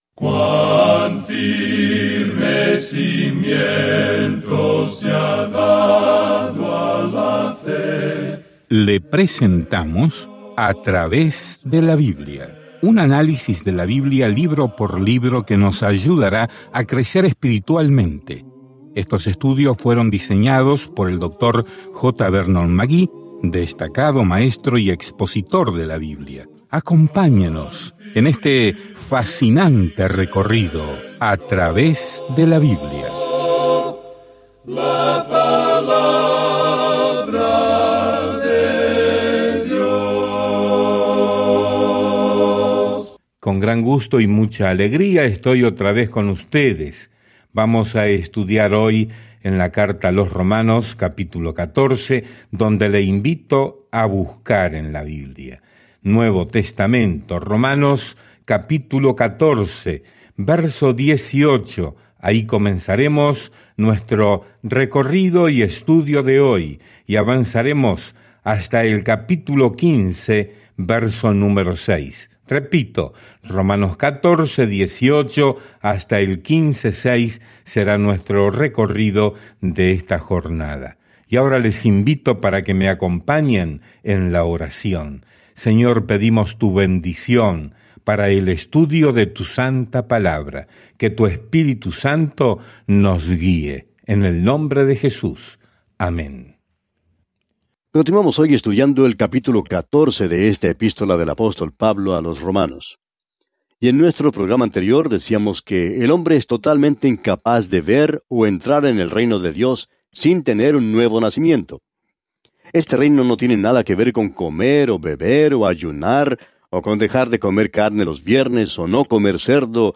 Y cómo alguien puede creer, ser salvo, librarse de la muerte y crecer en la fe. Viaja diariamente a través de Romanos mientras escuchas el estudio en audio y lees versículos seleccionados de la palabra de Dios.